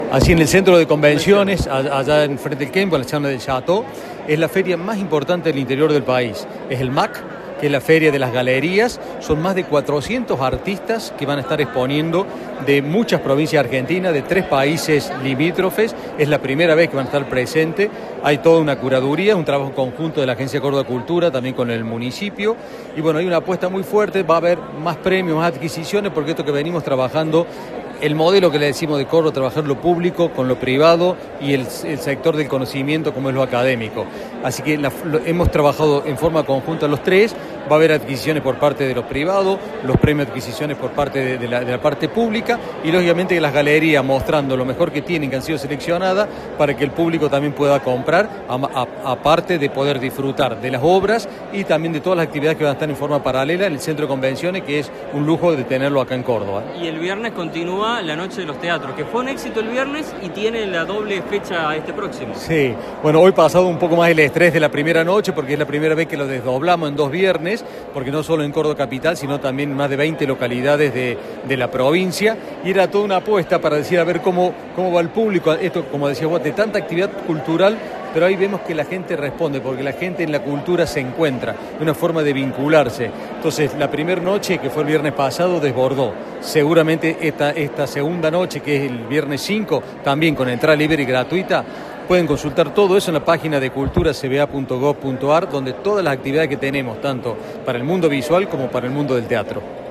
En diálogo con Cadena 3, el presidente de la Agencia Córdoba Cultura, Raúl Sansica, destacó la importancia del evento: “Es la feria más importante del interior del país. Son más de 400 artistas que van a estar exponiendo, provenientes de muchas provincias argentinas y de Brasil que vienen por primera vez”.
Informe